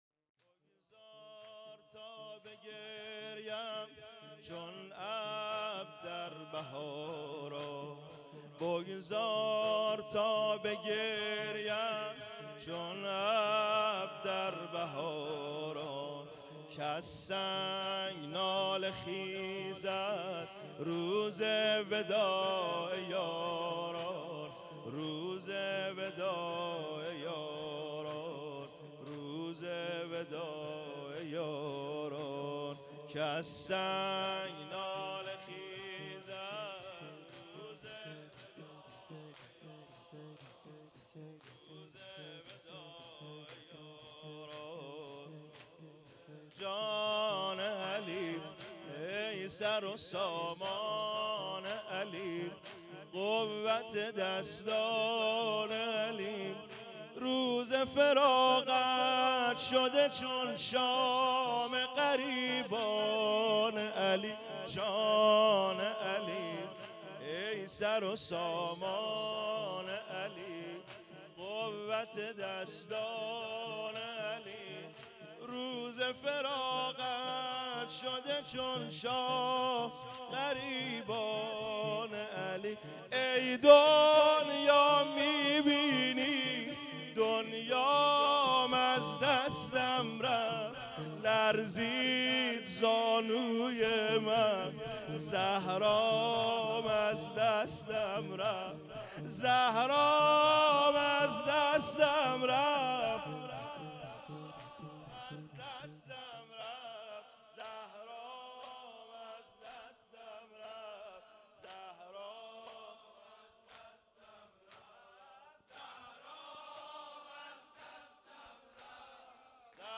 شب سوم مراسم دهه دوم فاطمیه ۹۹